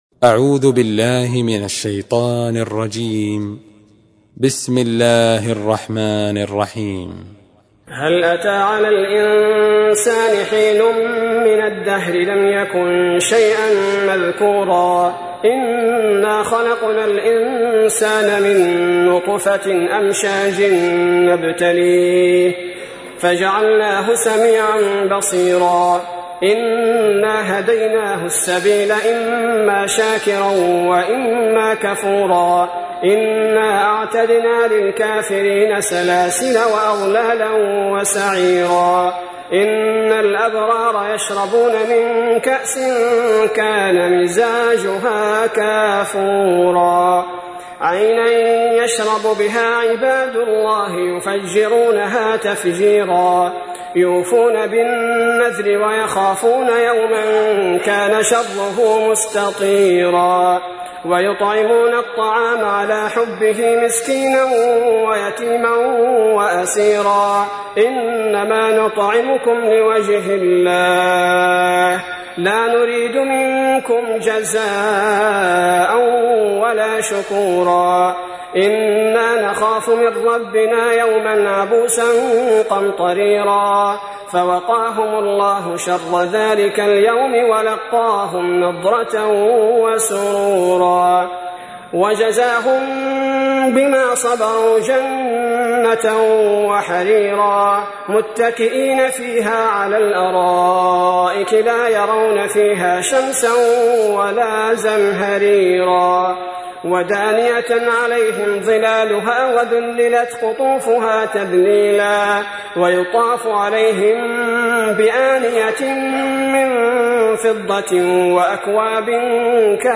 تحميل : 76. سورة الإنسان / القارئ عبد البارئ الثبيتي / القرآن الكريم / موقع يا حسين